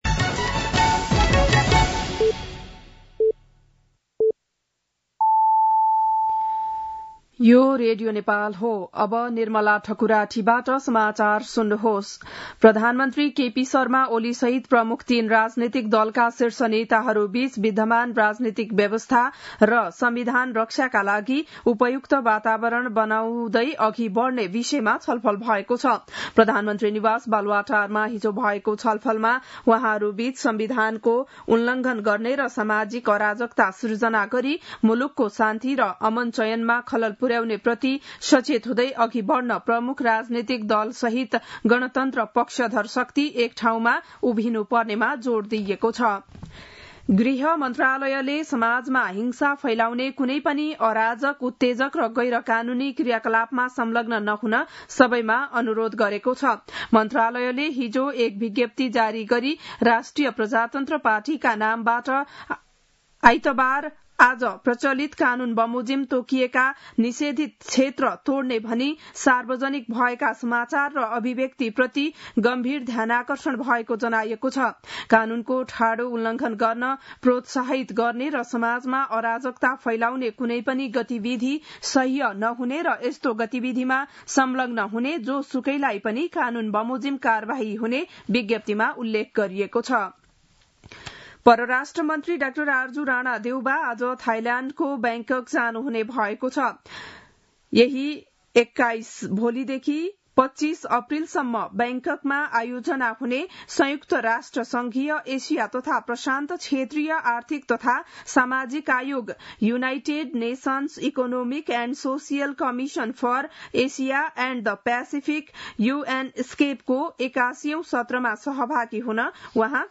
बिहान ११ बजेको नेपाली समाचार : ७ वैशाख , २०८२